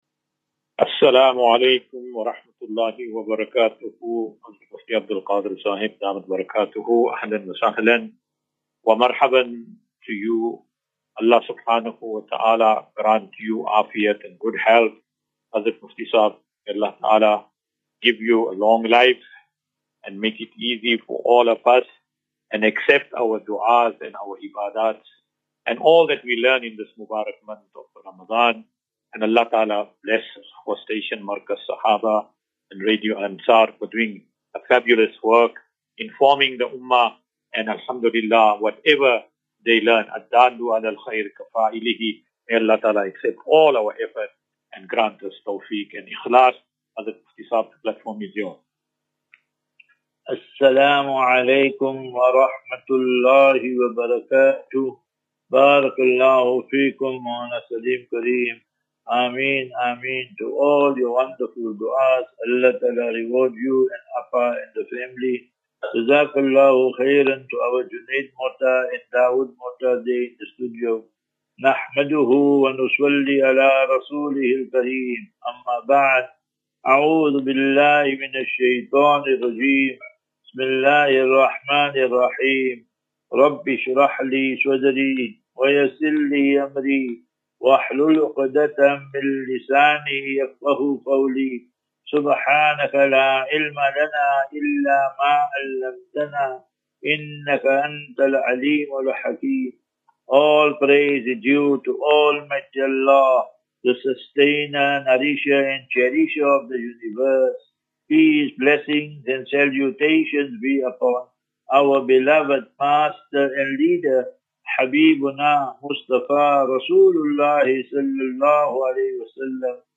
As Safinatu Ilal Jannah Naseeha and Q and A 8 Mar 08 March 2025.